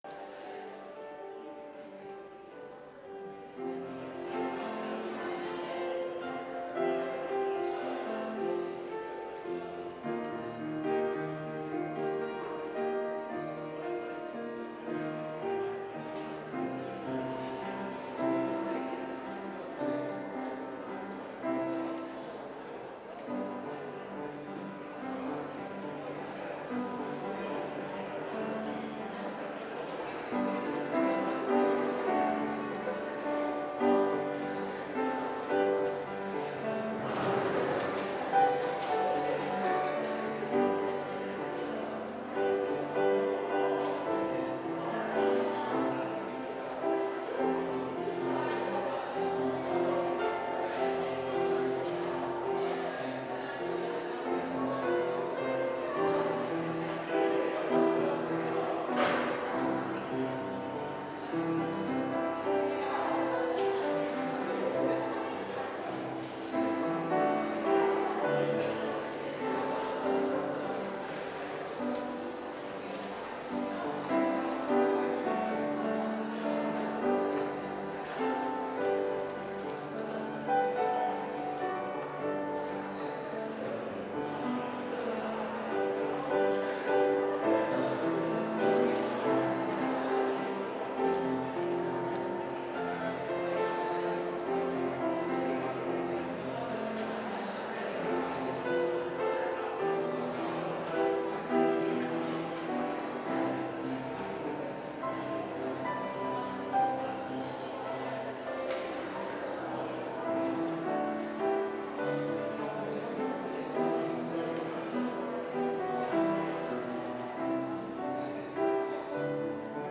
Complete service audio for Chapel - September 9, 2020